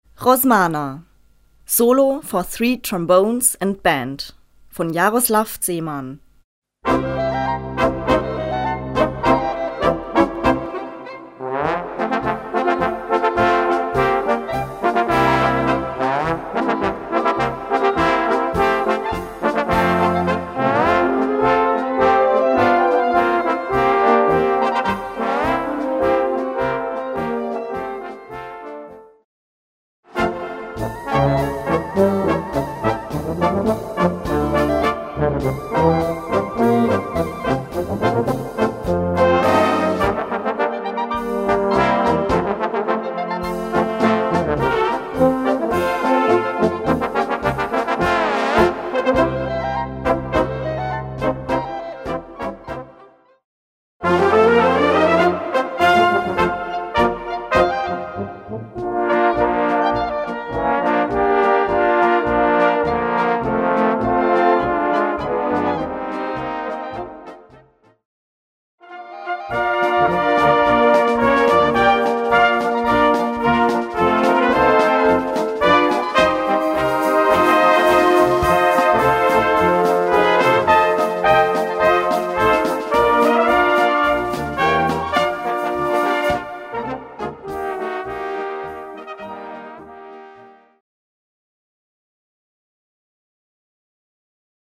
Gattung: Polka für 3 Posaunen und Blasorchester
Besetzung: Blasorchester